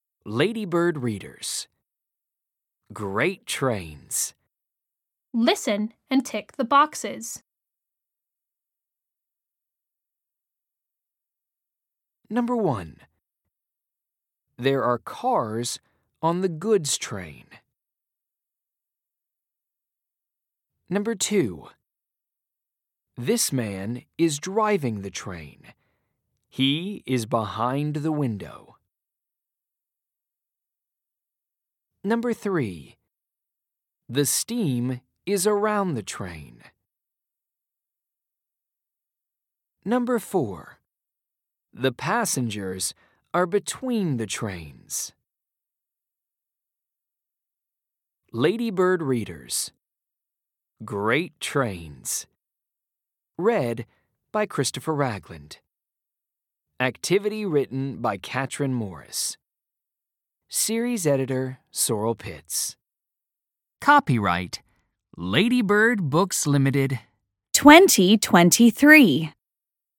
Audio US